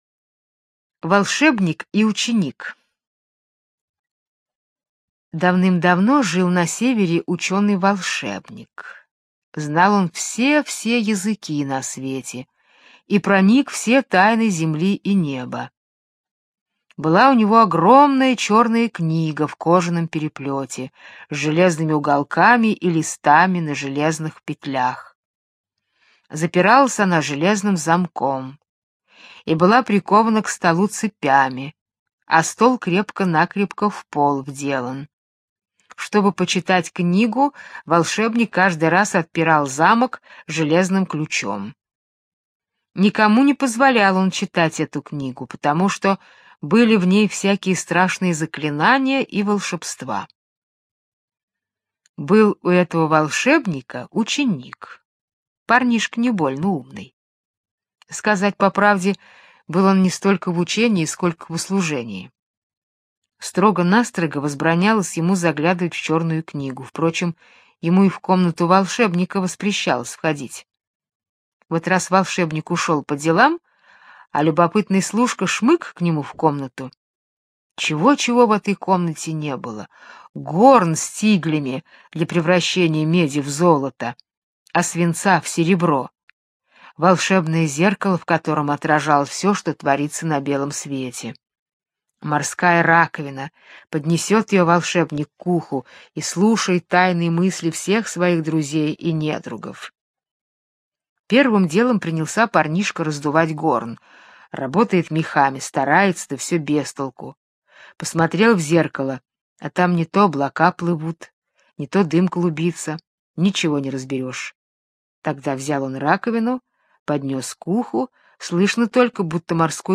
Волшебник и ученик – британская народная аудиосказка